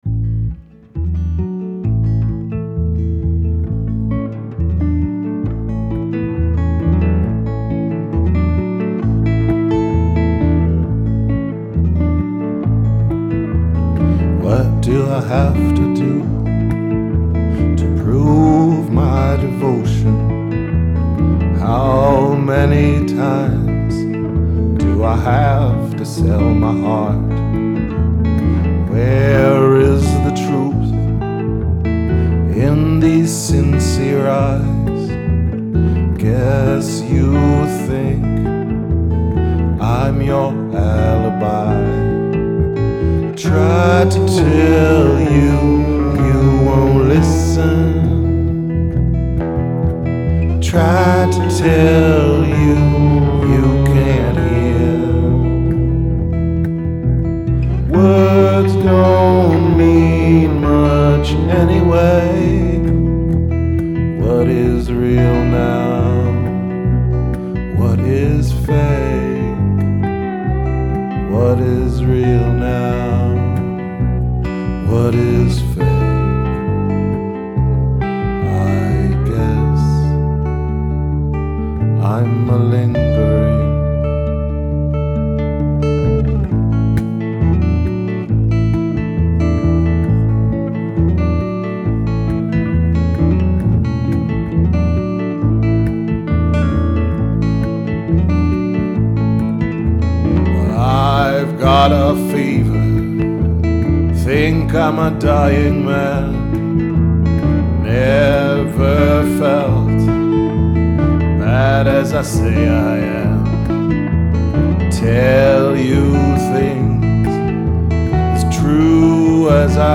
Rehearsals 3.3.2012